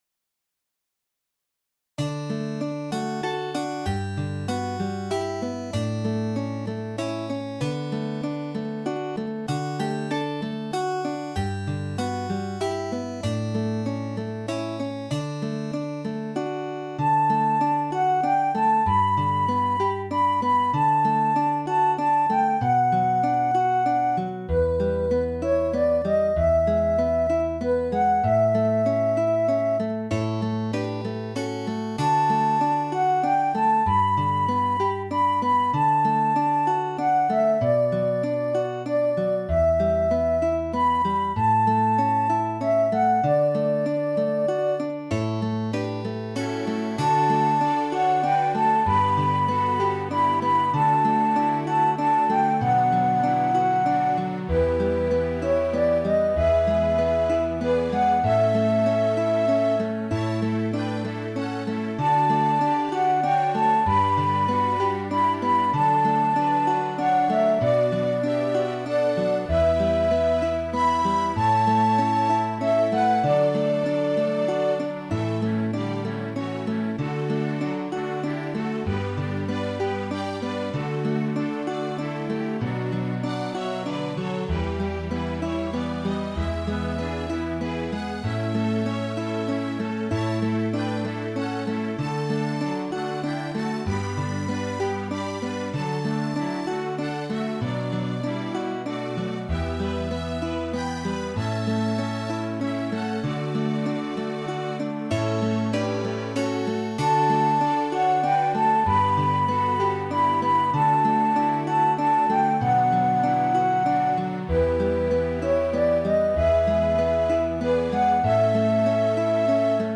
ストリングスの刻みのところでフェイドアウト。